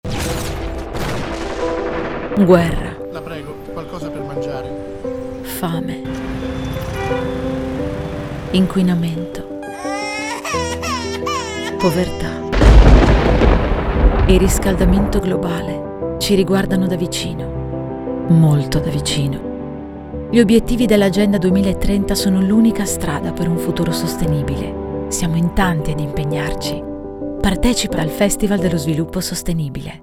Lo spot radio
È una chiamata per ognuno di noi: se non ci impegniamo in prima persona attraverso i nostri comportamenti quotidiani, ci accorgeremo un giorno che "la nostra indifferenza ha un prezzo", come ci ricorda la cantante Elisa che ha scelto di prestare la sua voce allo spot.